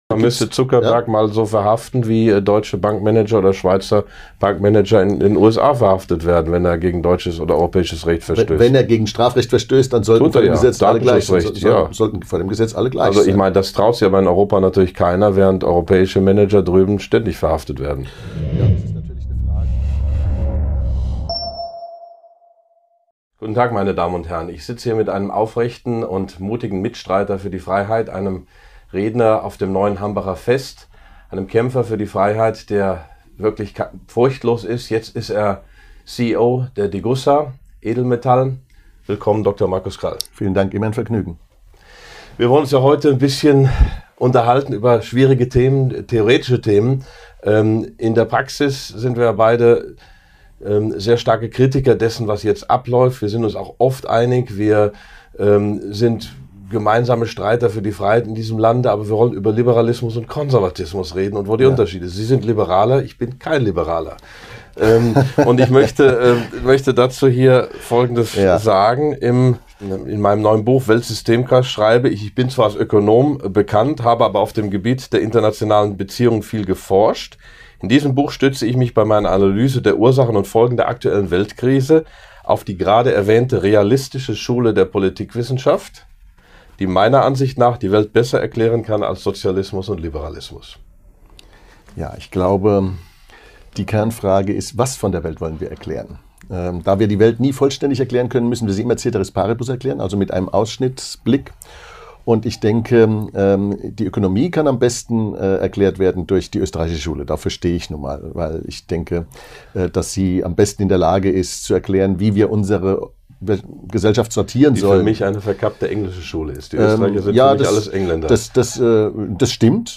Der konservativ-liberale Ökonom Dr. Markus Krall und der „preußische Sozialist“ Prof. Dr. Max Otte debattieren...
Das Gespräch wurde im Juni 2020 aufgezeichnet.